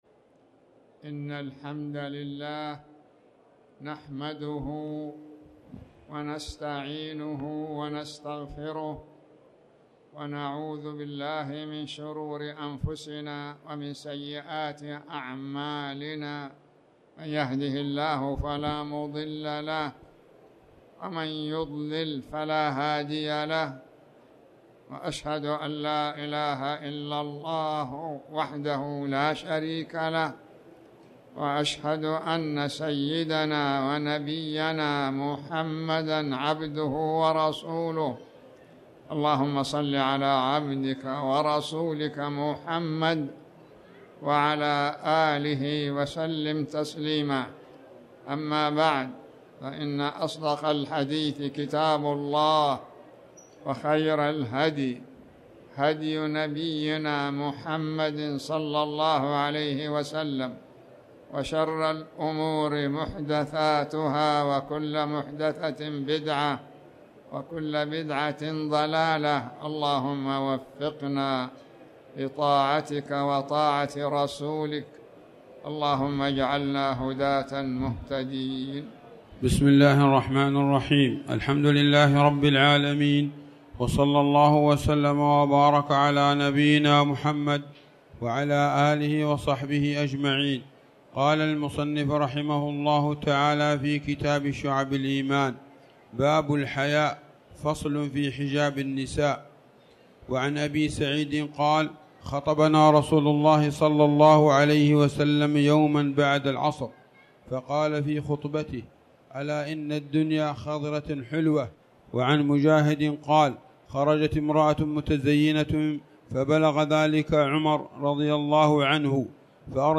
تاريخ النشر ٢٩ رجب ١٤٣٩ هـ المكان: المسجد الحرام الشيخ